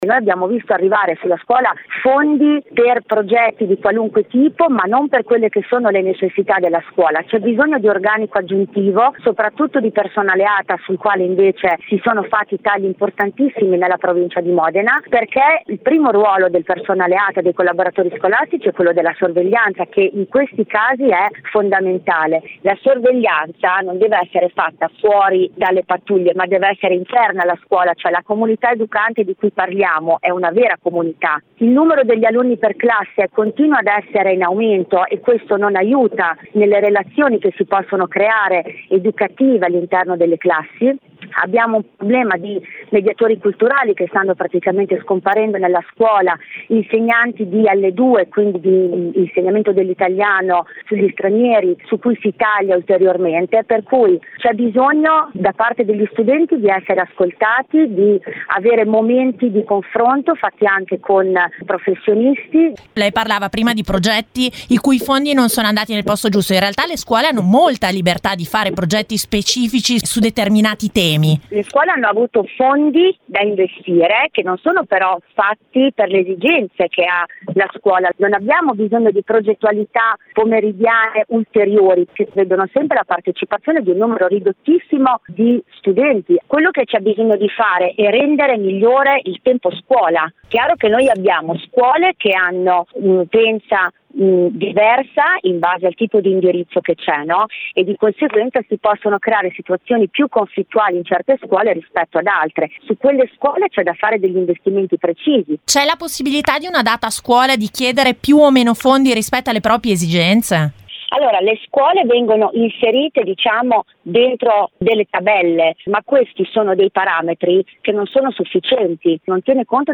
intervistata